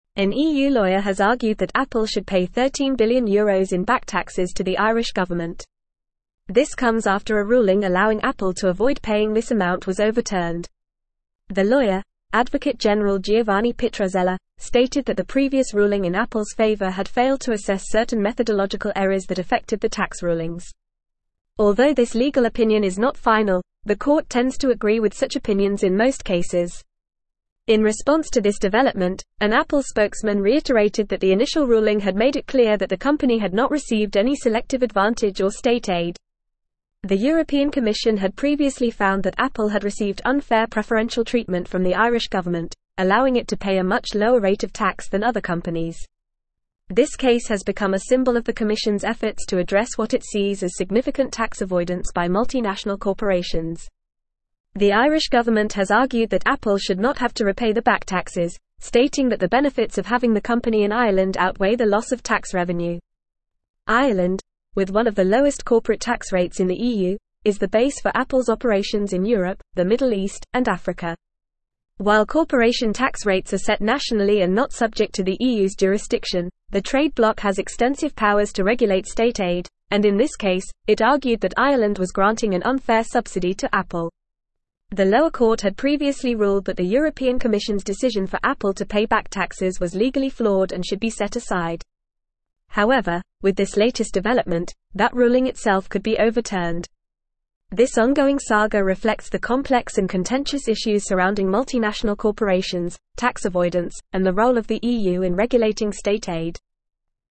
Fast
English-Newsroom-Advanced-FAST-Reading-EU-Adviser-Recommends-Overturning-Ruling-on-Apples-Taxes.mp3